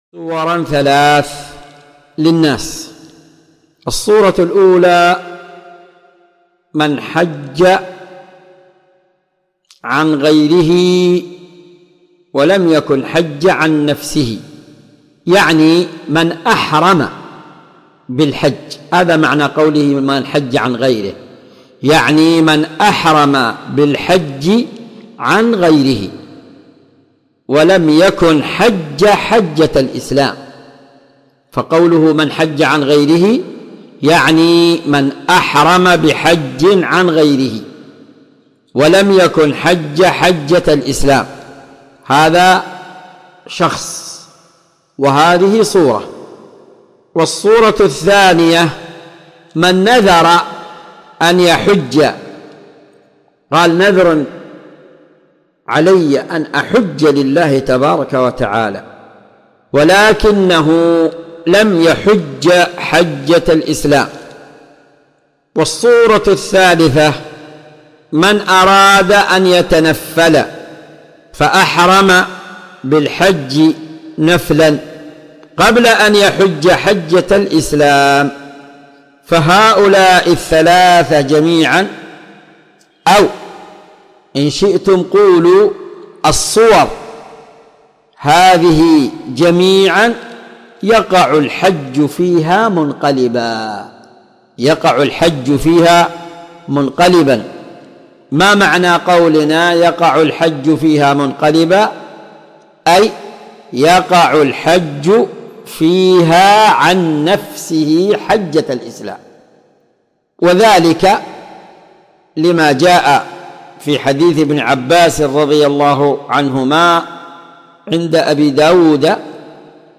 مقتطف من شرح كتاب الحج من عمدة الفقه الشريط الثالث .